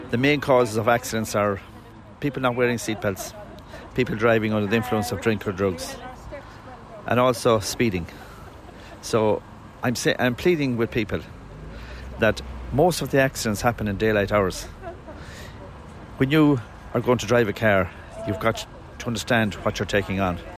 A safety campaign was launched yesterday, and Minister of State with responsibility for road Safety Sean Canney says drivers must be aware that cars are heavy machines: